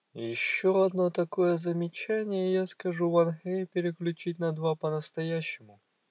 Nexdata/Russian_Speech_Data_by_Mobile_Phone at main